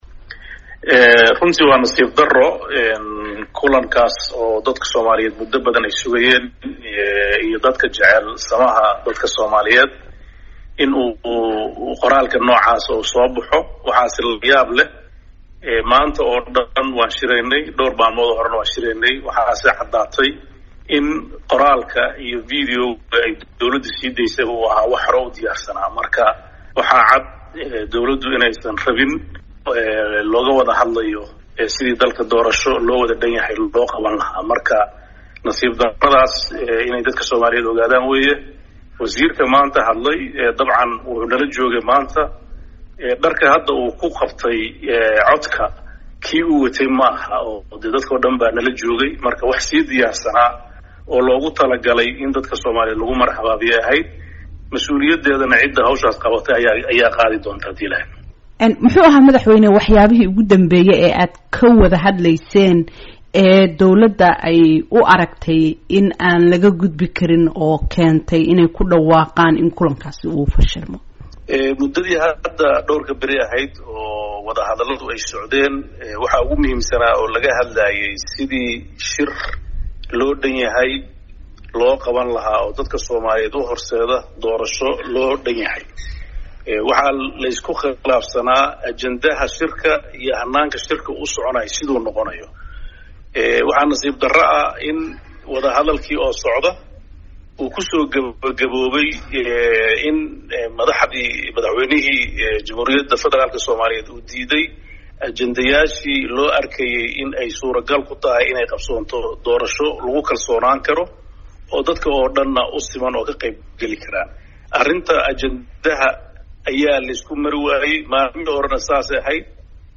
Wareysi: Madaxweyne Deni oo ka hadlay shirka fashilmay ee Afisyooni